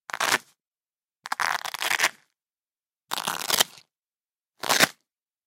粘扣带
描述：撕开一个钱包。
标签： 撕裂 魔术贴
声道立体声